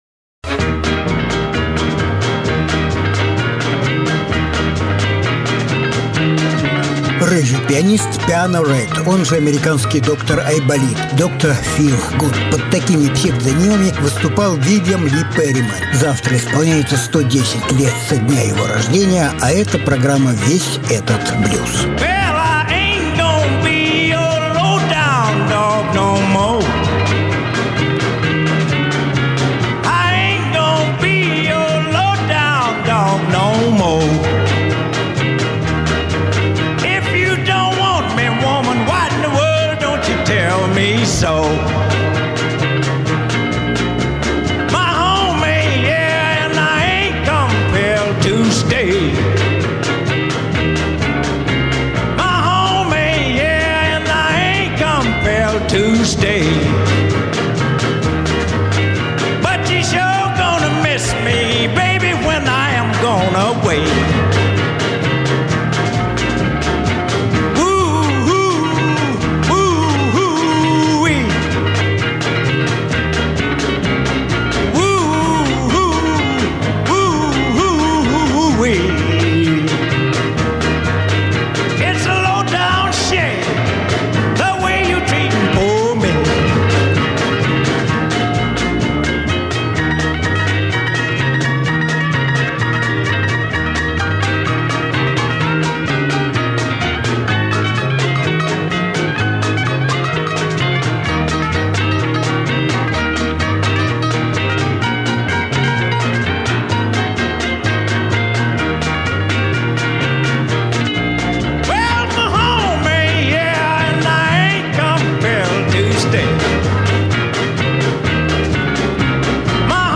Жанр: Блюза и блюзики